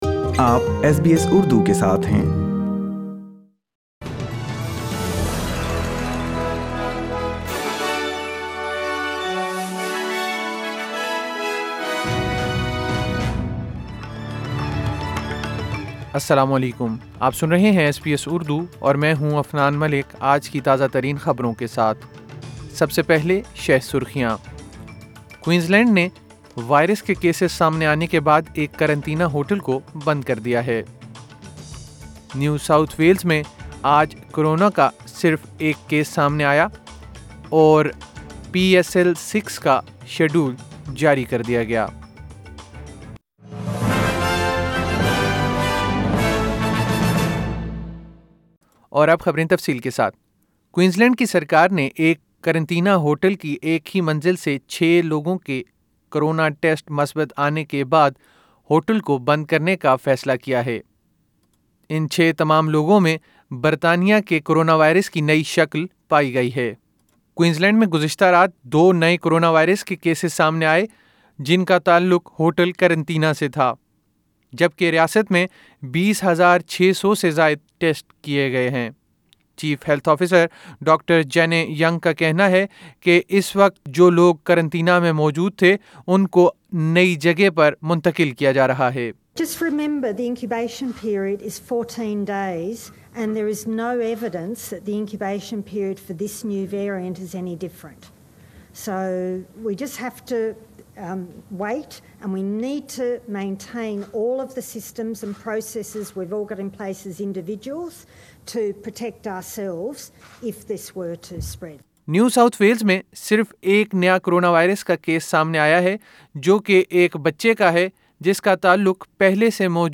ایس بی ایس اردو خبریں 13 جنوری 2021